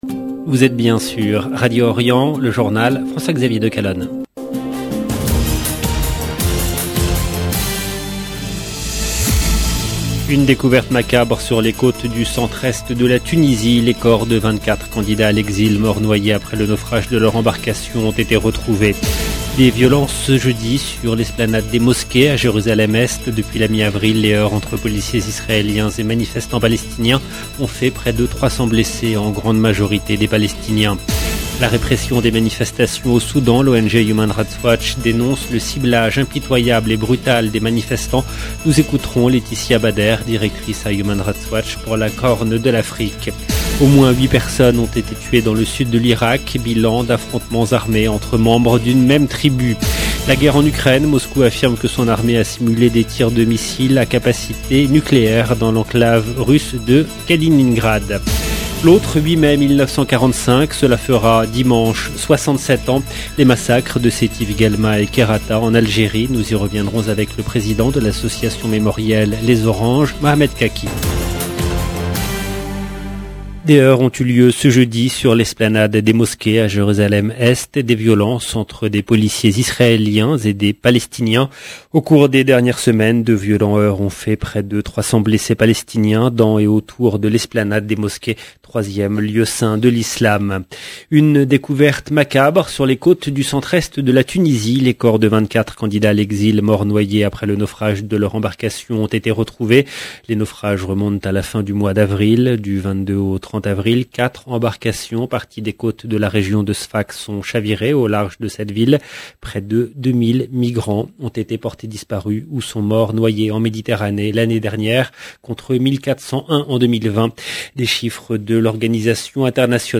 LE JOURNAL DU SOIR EN LANGUE FRANCAISE DU 5/5/2022